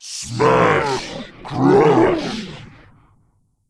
Hero Lines